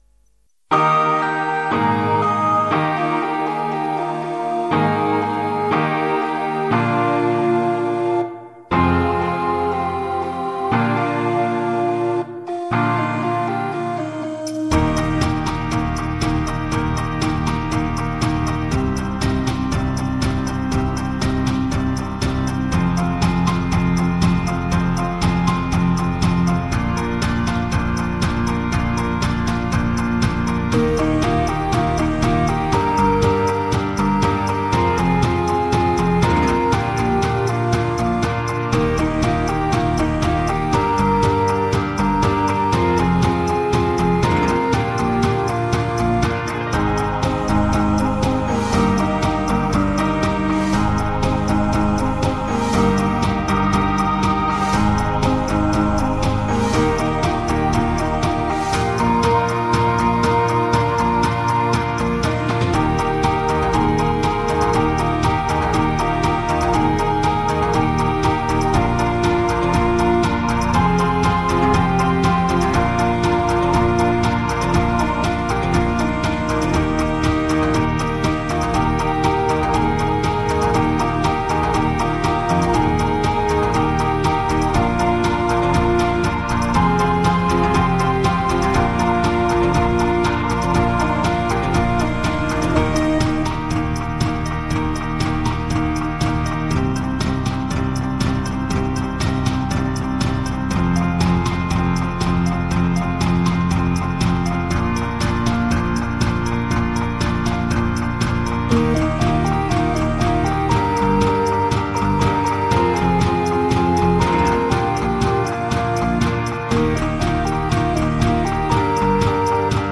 Moderato-Beat